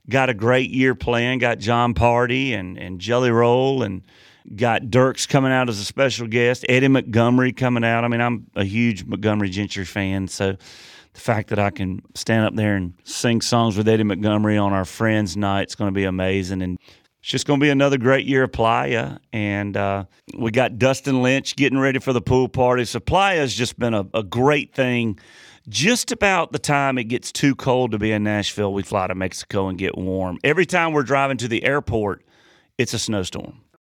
Audio / Luke Bryan is really excited about his Crash My Playa event this year, and he says it's perfect timing for the getaway.